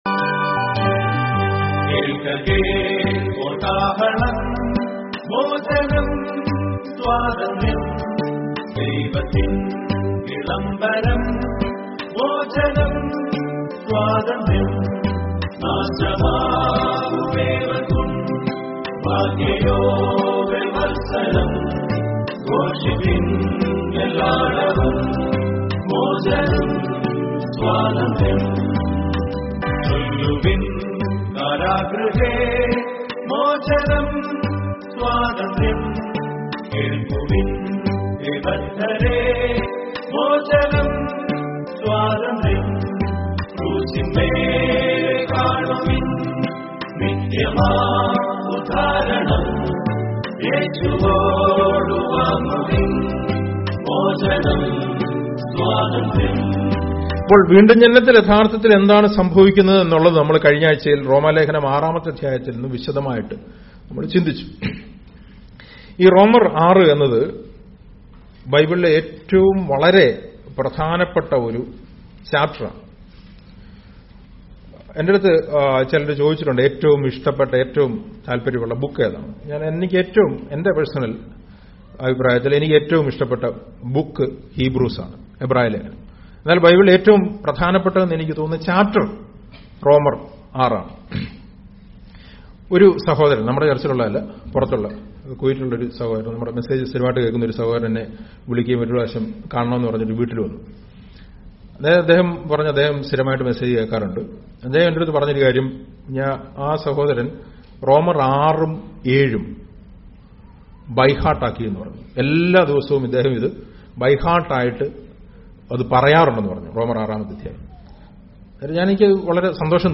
SERMONS
പാപത്തിൻ Bible Study